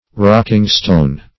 Rocking-stone \Rock"ing-stone`\, n.